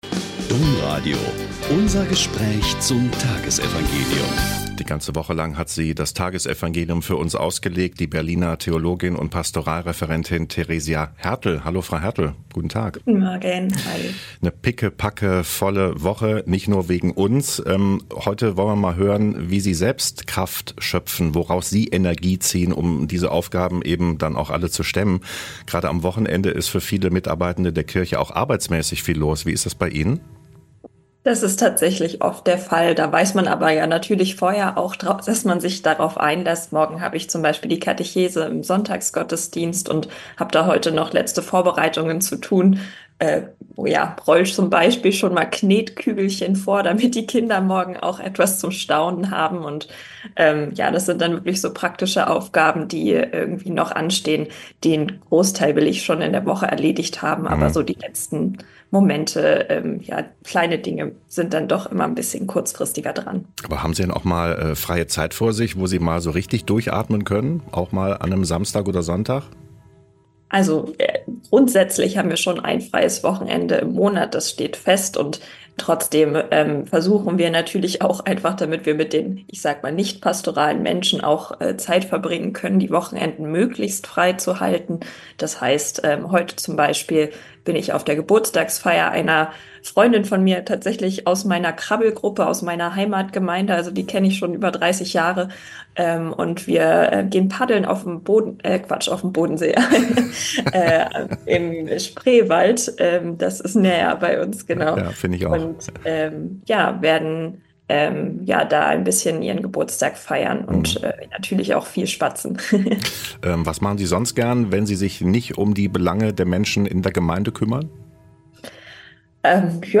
Mt 9,14-17 - Gespräch